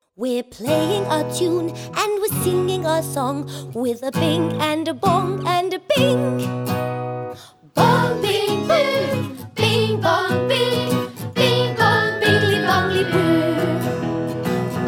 Extended Comedy/Novelty 1:27 Buy £1.50